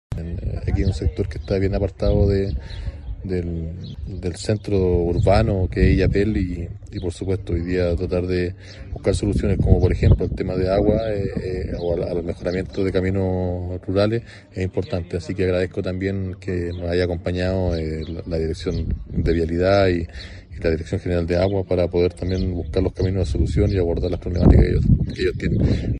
En la sede social de la localidad de Matancilla, se llevó a cabo una reunión de trabajo en que los vecinos del sector dieron a conocer sus necesidades al Delegado presidencial de Choapa, Jonatan Vega, quién acompañado de los servicios públicos como Vialidad, Dirección General de Aguas y la Municipalidad de Illapel escucharon atentamente cada una de las exposiciones de los participantes que se centraron en dos muy importantes: el arreglo del camino principal de acceso al sector y una mayor coordinación respecto a la entrega del agua potable.
Por su parte el Delegado Presidencial de Choapa, Jonatan Vega, sintetizó lo canalizado durante la actividad
Reunion-Matancilla-Cuna-02-Jonatan-Vega-DPP-Choapa.mp3